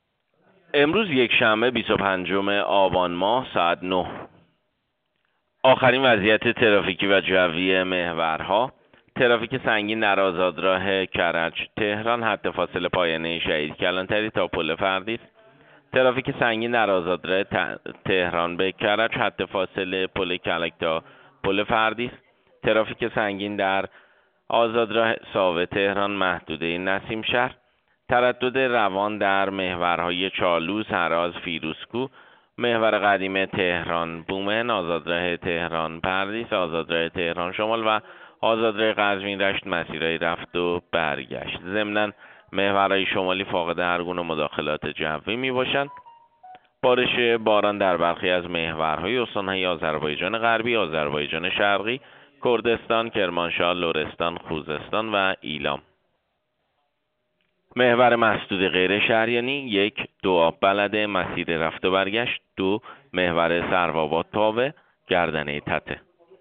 گزارش رادیو اینترنتی از آخرین وضعیت ترافیکی جاده‌ها ساعت ۹ بیست و پنجم آبان؛